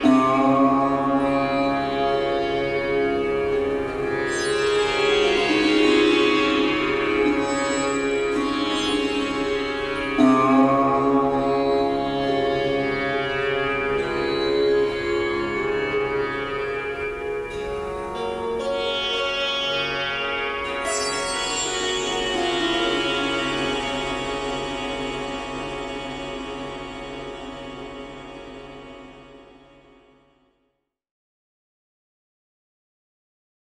Sitar
cordófono
meditación
oriental
relajación